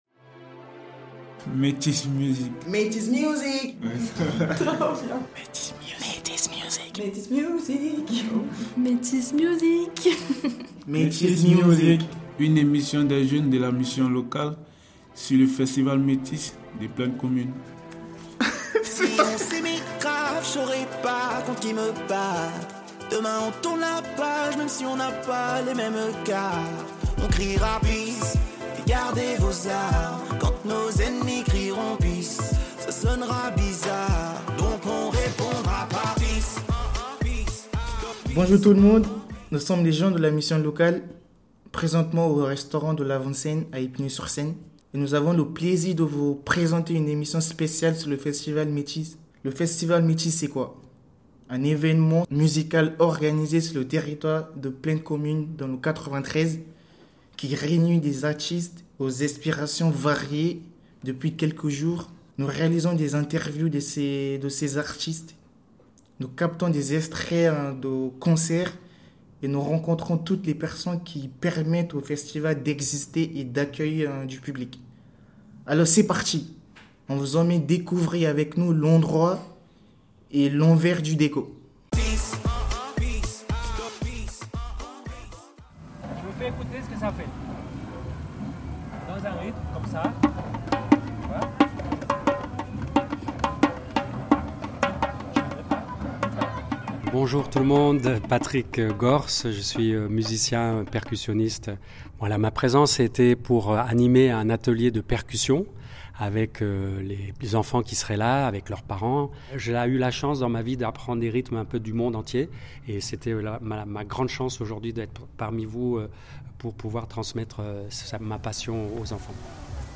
Avec un casque et un micro, les jeunes de la mission locale se sont rendus au festival Métis de Plaine Commune pour capter des sons, enregistrer des concerts et mener des interviews. Percussionniste, chanteur lyrique, professeur de danse, pianiste, bénévoles, toutes ces rencontres sont compilées dans l’émission “Metis Music”, qui nous plonge dans l’ambiance de ce festival musical qui s’est tenu du 30 juin au 13 juillet 2024 dans différentes villes du territoire.
Au programme : sonorités indiennes et onomatopées. Il a ensuite assisté au concert décapant du Fanfaraï Street Band qui mêle des cuivres jazzy, afro-cubain et tziganes.